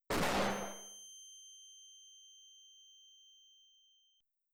Flash Grenade Effect.wav